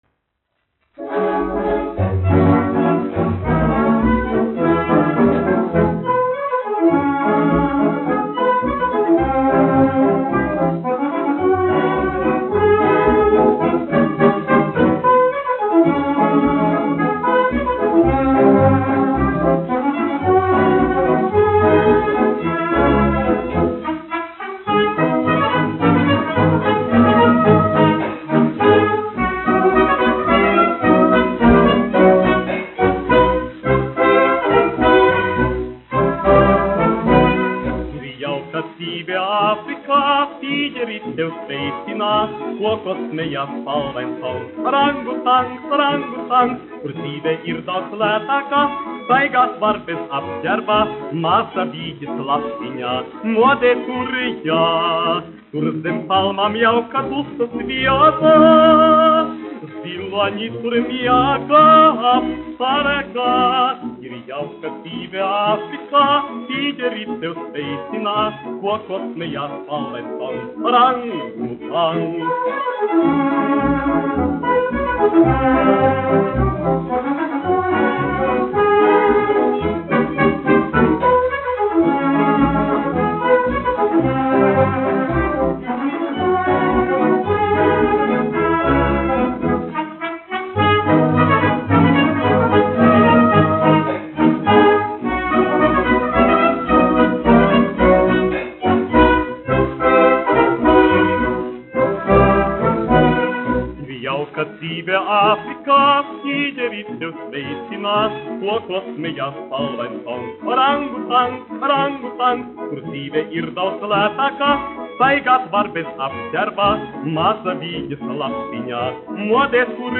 1 skpl. : analogs, 78 apgr/min, mono ; 25 cm
Fokstroti
Populārā mūzika -- Latvija
Skaņuplate